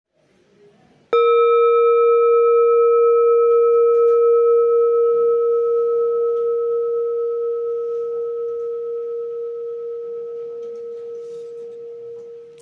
Material Seven Bronze Metal
Jambati Singing Bowl
It can discharge an exceptionally low dependable tone.